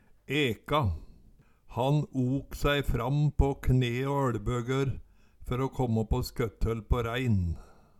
Høyr på uttala Ordklasse: Verb Attende til søk